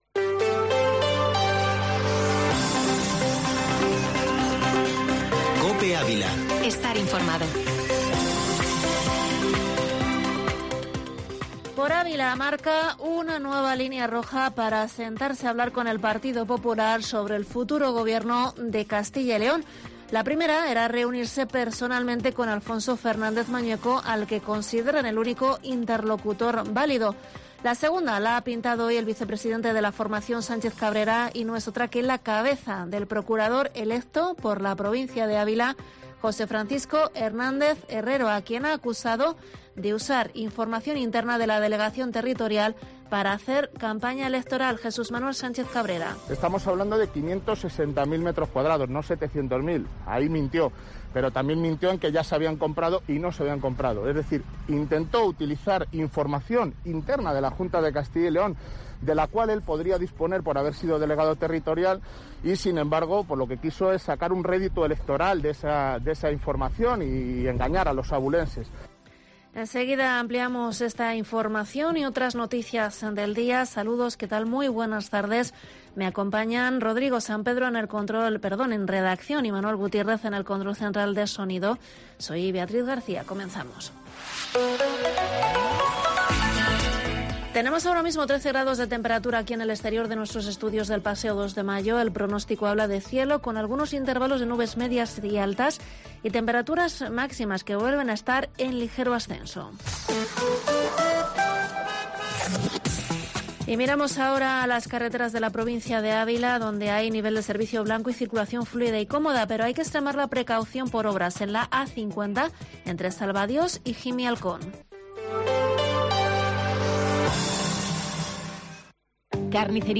Informativo Mediodía COPE en Ávila 23/2/22